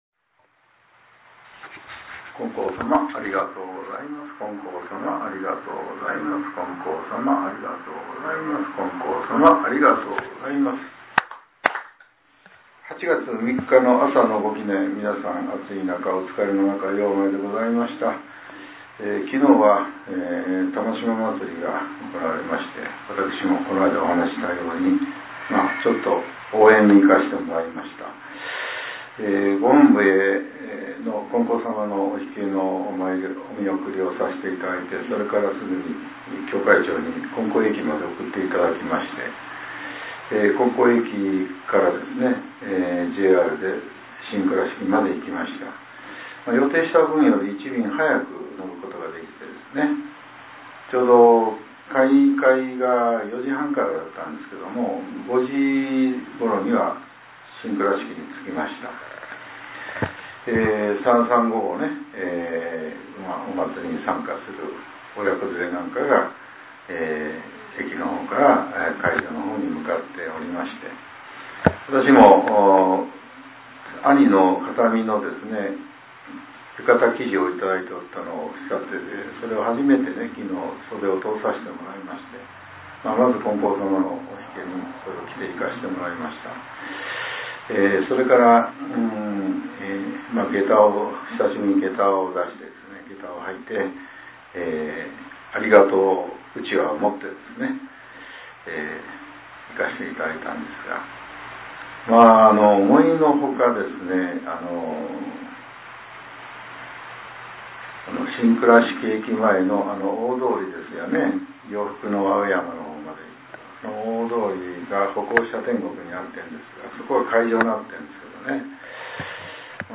令和７年８月３日（朝）のお話が、音声ブログとして更新させれています。